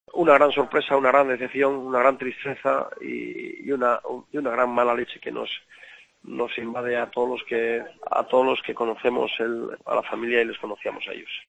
José Luis Navarro, alcalde de Abadiño: ''Una gran tristeza que nos invade a todos''